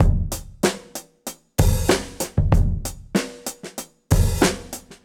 Index of /musicradar/dusty-funk-samples/Beats/95bpm
DF_BeatA_95-02.wav